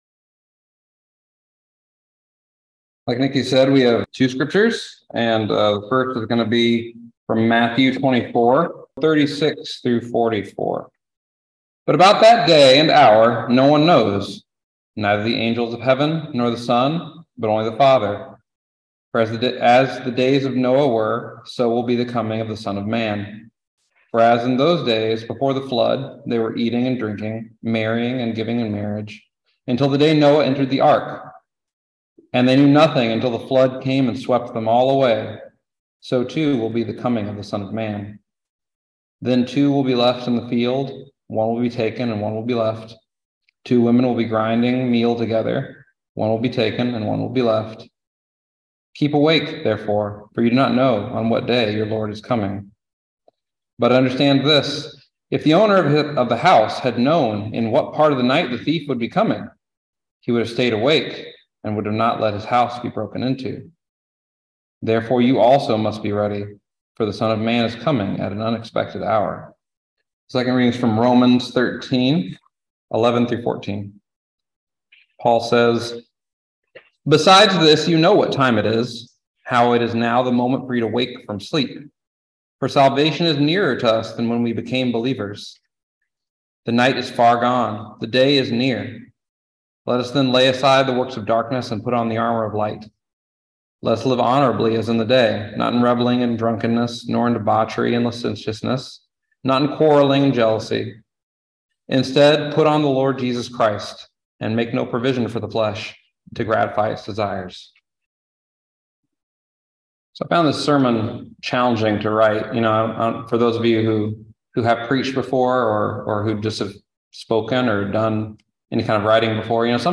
Message for November 27, 2022
Listen to the most recent message from Sunday worship at Berkeley Friends Church, “An Unexpected Hour.”